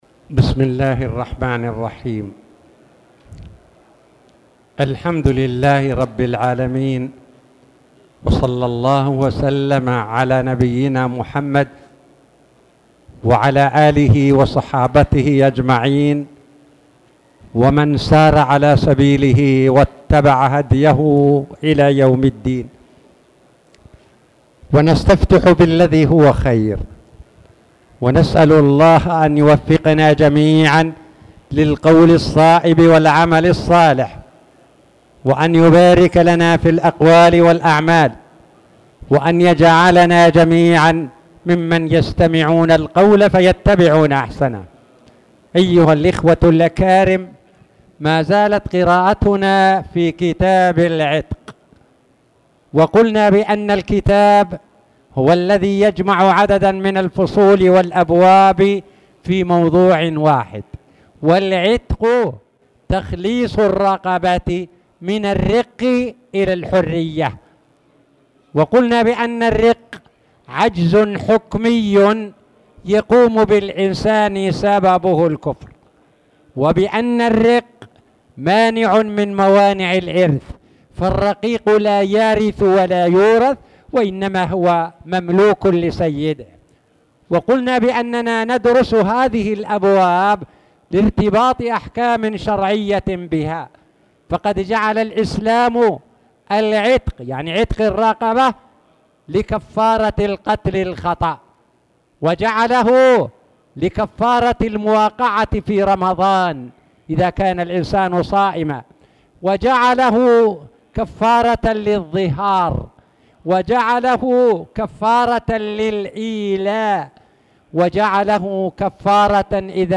تاريخ النشر ١٧ محرم ١٤٣٨ هـ المكان: المسجد الحرام الشيخ